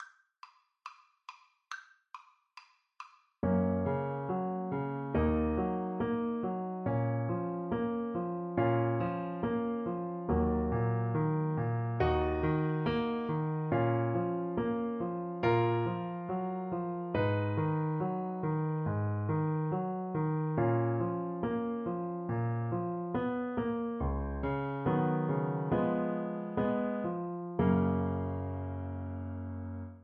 Classical Puccini, Giacomo One Fine Day from Madam Butterfly Alto Saxophone version
Alto Saxophone
Gb major (Sounding Pitch) Eb major (Alto Saxophone in Eb) (View more Gb major Music for Saxophone )
Classical (View more Classical Saxophone Music)